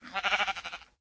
sheep